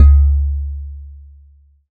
Udu2.aiff